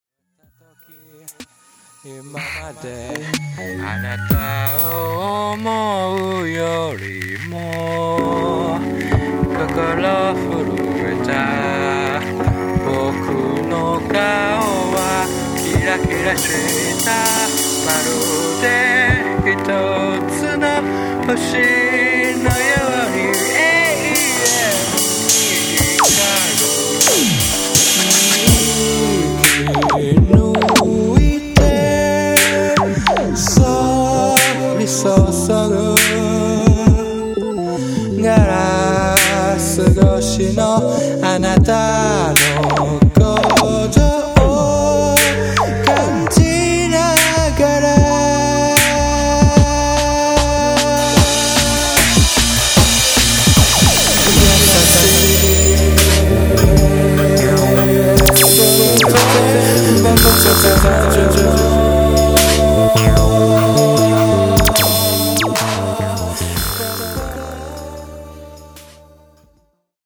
プログレッシブ・ロックやJazz、現代音楽のさまざまな手法が何の関連性もなく次々と出てきます。